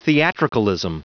Prononciation du mot theatricalism en anglais (fichier audio)
Prononciation du mot : theatricalism